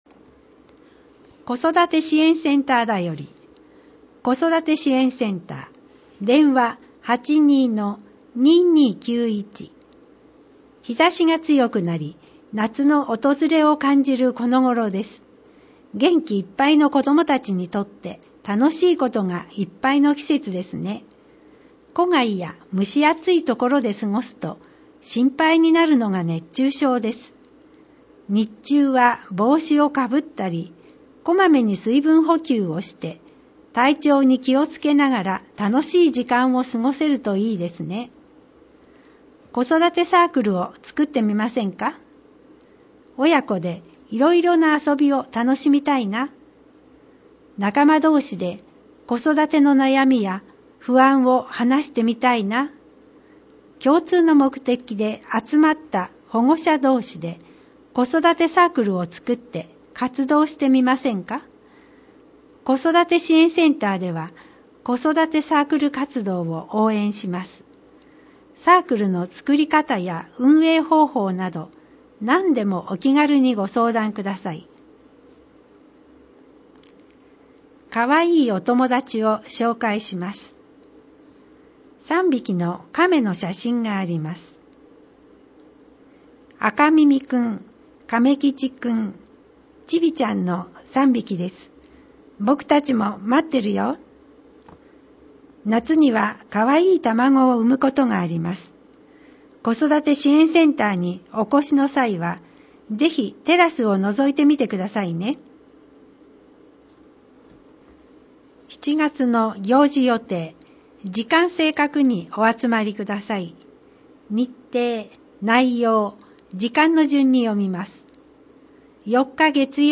また、音訳ボランティアサークルおとわの会のみなさんによる広報の音訳版のダウンロードもご利用ください。
（PDF文書）   広報音訳版ダウンロード（制作：おとわの会）